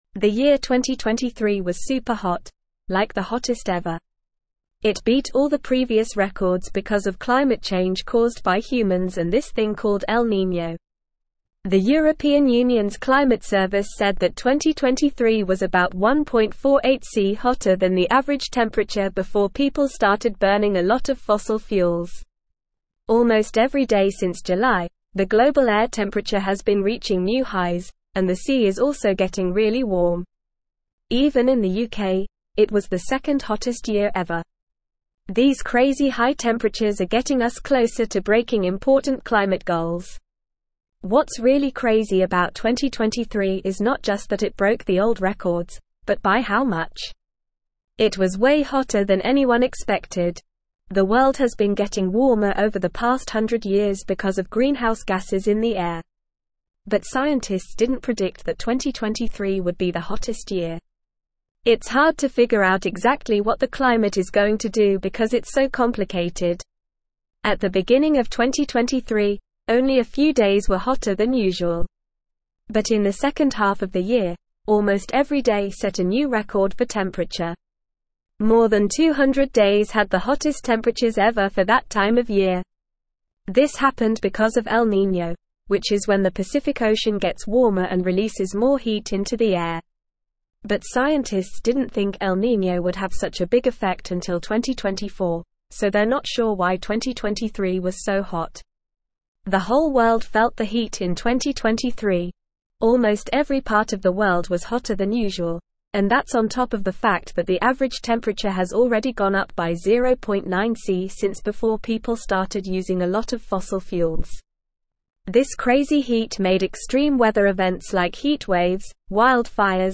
Normal
English-Newsroom-Upper-Intermediate-NORMAL-Reading-2023-Hottest-Year-on-Record-Urgent-Climate-Action-Needed.mp3